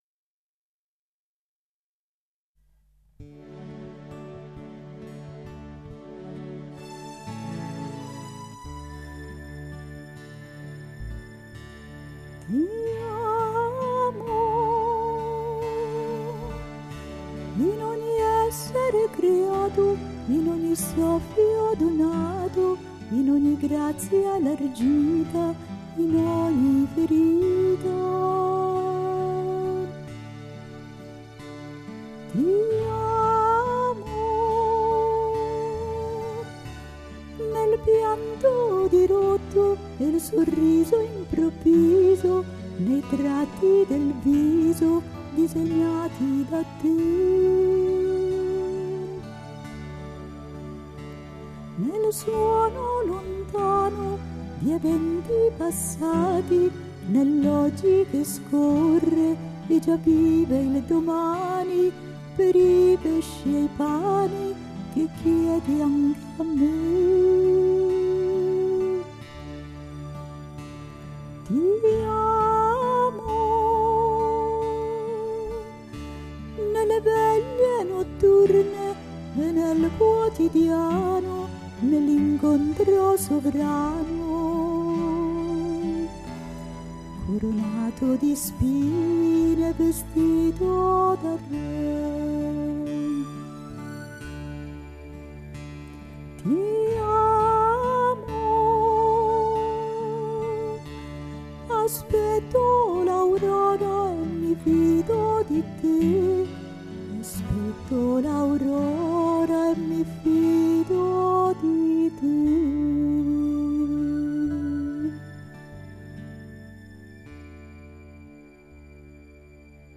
Ti amo canto mp3